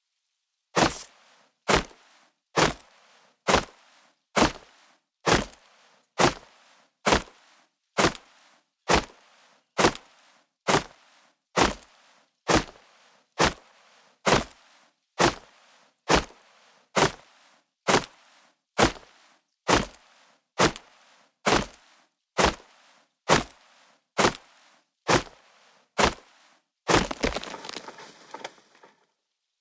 Chopping Down Tree Sound Effect Free Download
Chopping Down Tree